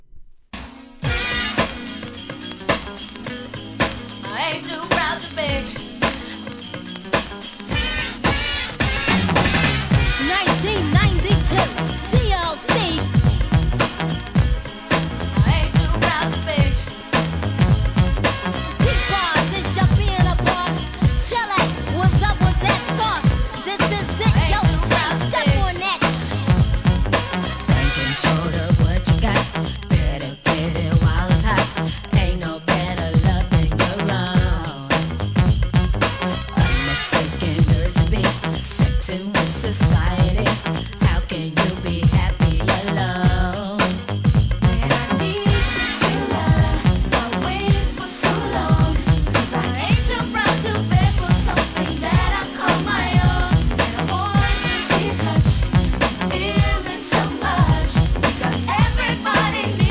HIP HOP/R&B
NEW JACK SWING仕上げの1992年1st!!